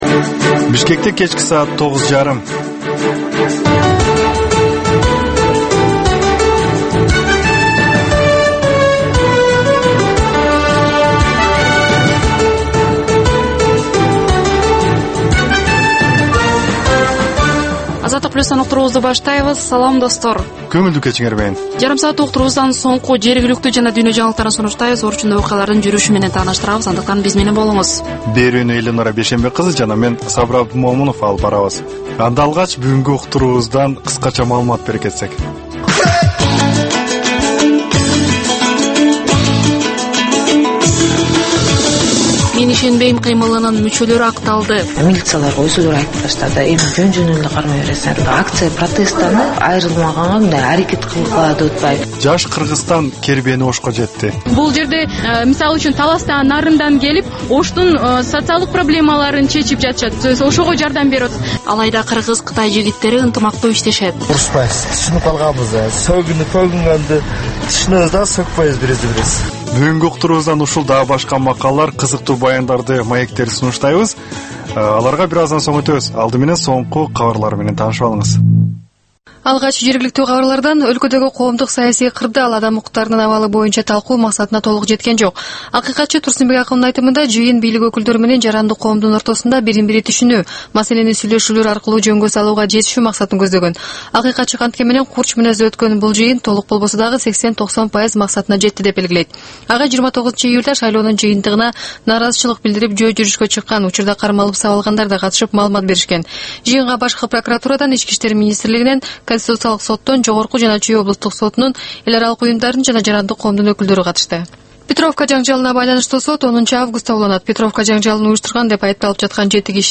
Бул жаштарга арналган кечки үналгы берүү жергиликтүү жана эл аралык кабарлардан, репортаж, маек, баян жана башка берүүлөрдөн турат. "Азаттык үналгысынын" бул жаштар берүүсү Бишкек убактысы боюнча саат 21:30дан 22:00ге чейин обого чыгат.